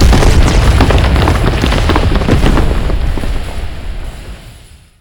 impact.wav